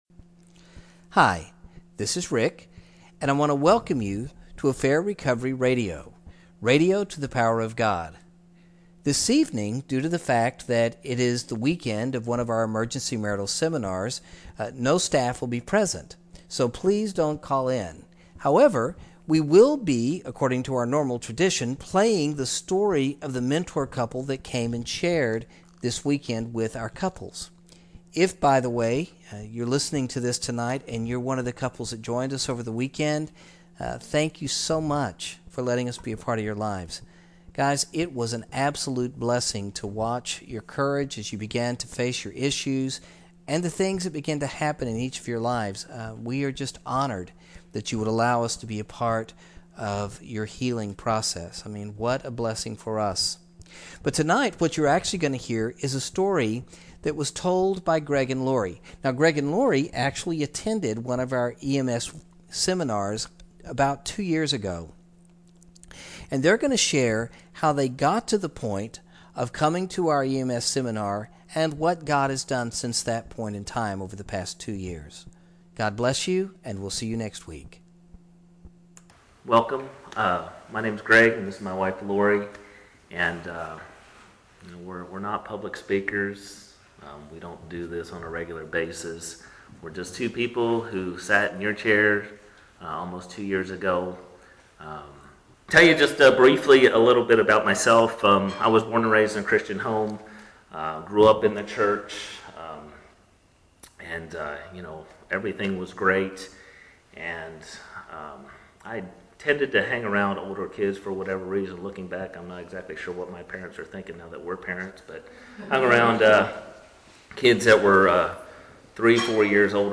A mentor couple shares the story of their marriage. Spanning two continents, three states, assorted failures and triumphs leading to the very brink of divorce and back again.